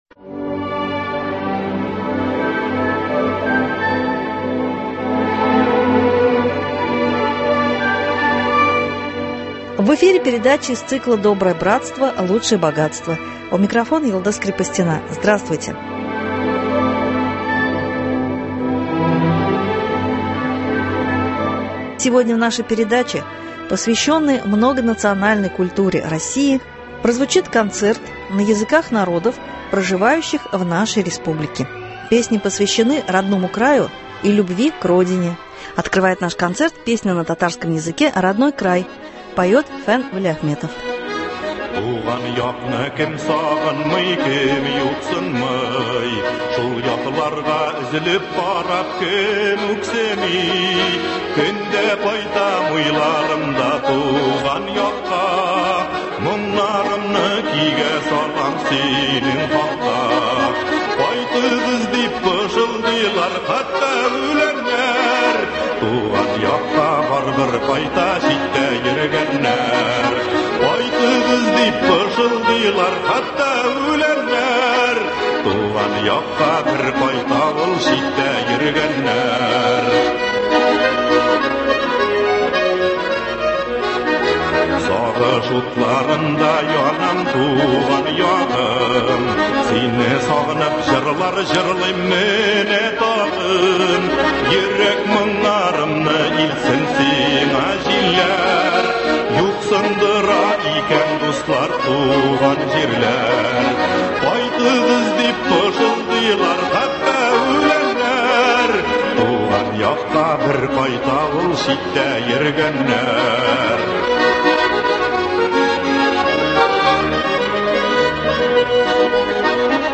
Концерт на языках народов Татарстана - песни о Родине.